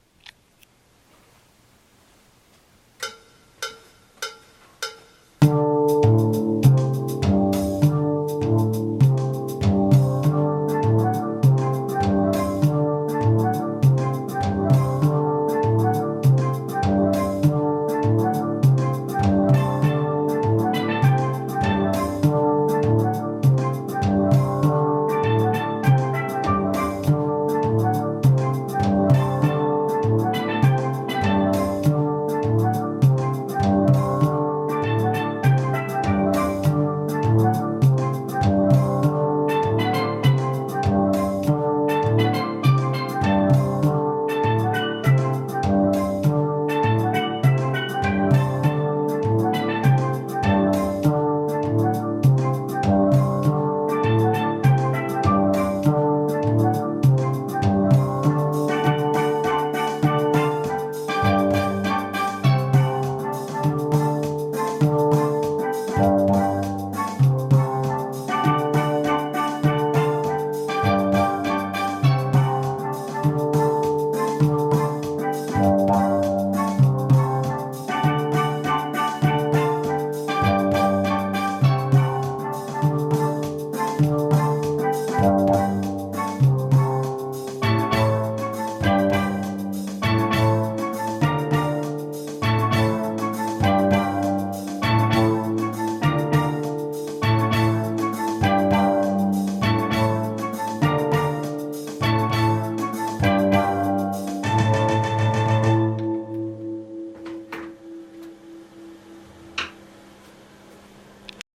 BASSE CALL OF THE WATER DANCER FILM .mp3